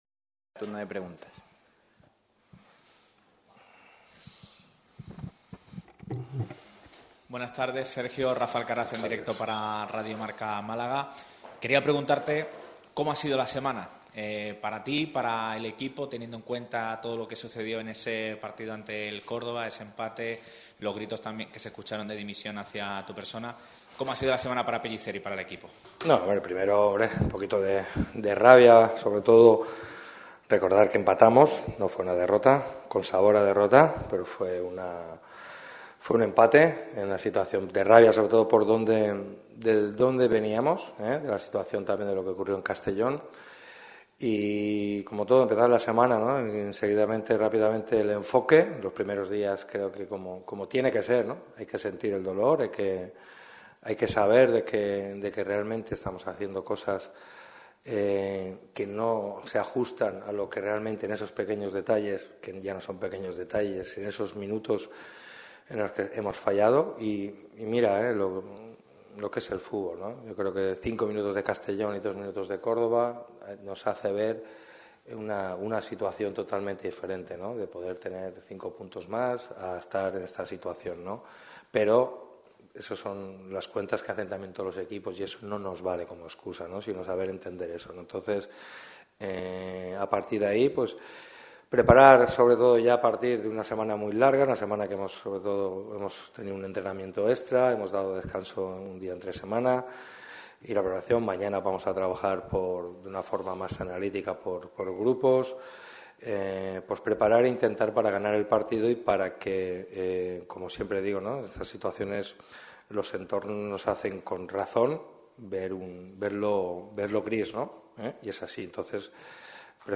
Comparecencia de Sergio Pellicer íntegra
Ha sido una comparecencia extensa.